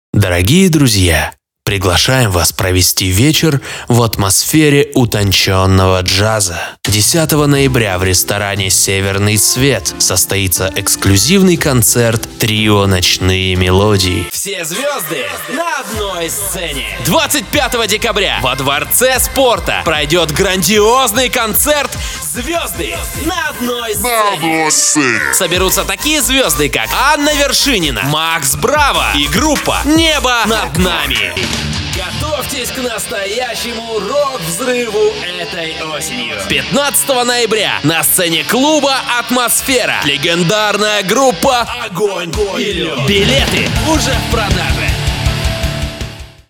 Конденсаторный микрофон Scarlett CM25 MkIII; Аудио-интерфейс: Lexicon Omega, Scarlett Focusrite Solo, Scarlett Focusrite 4i4 3Gen
Демо-запись №2 Скачать